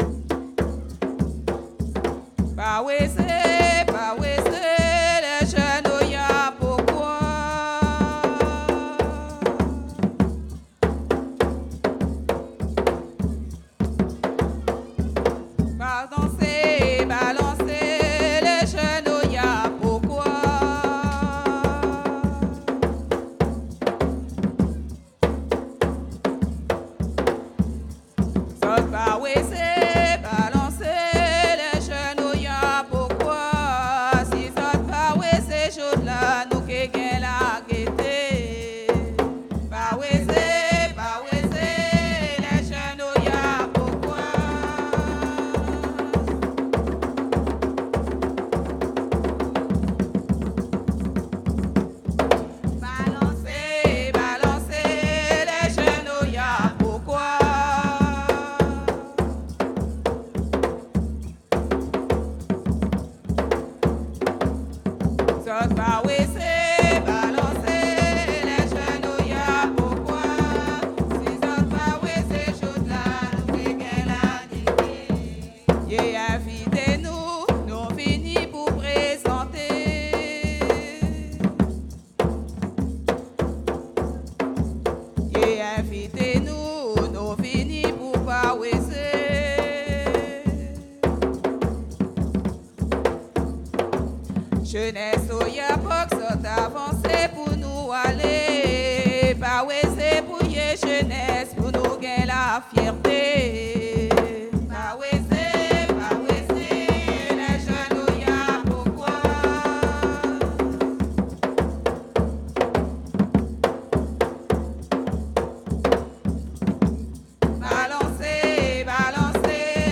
Soirée Mémorial
danse : léròl (créole)
Pièce musicale inédite